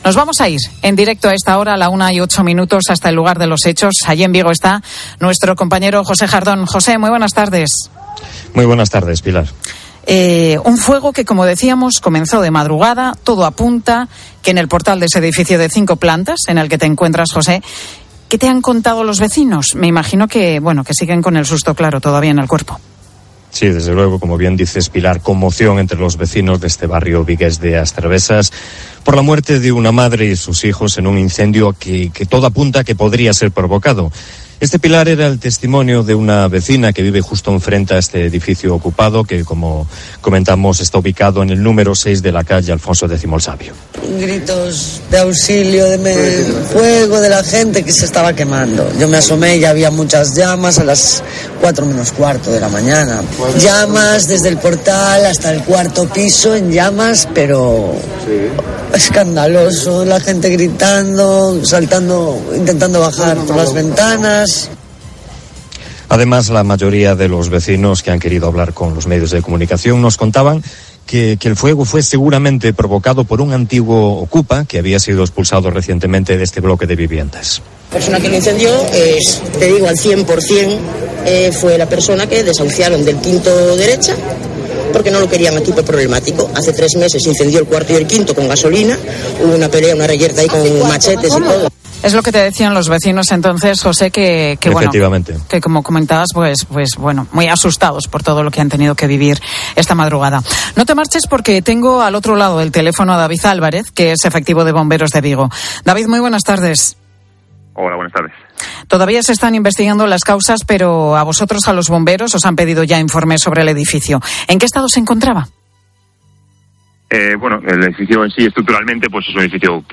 Uno de los bomberos que ha participado en las tareas de extinción explica en MEDIODÍA COPE que ha sido un fuego muy complicado para ellos